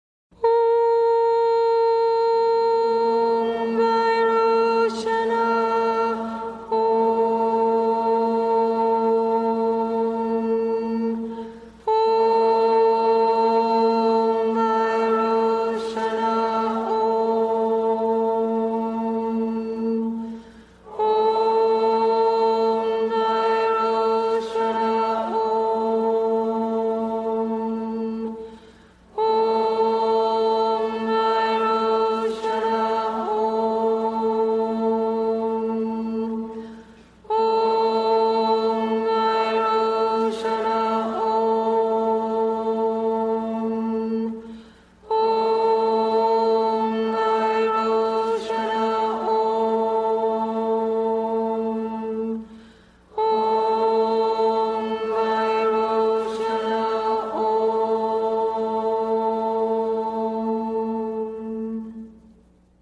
mantra-vajrochany.mp3